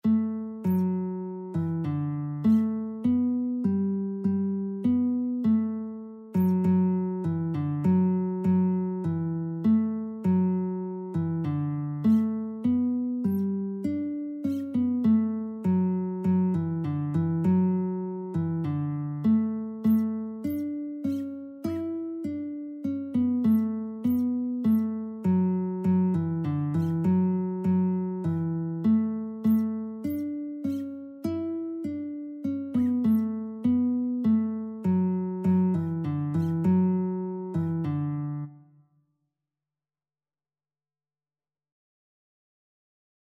Christian Christian Lead Sheets Sheet Music He Leadeth Me
4/4 (View more 4/4 Music)
D major (Sounding Pitch) (View more D major Music for Lead Sheets )
Classical (View more Classical Lead Sheets Music)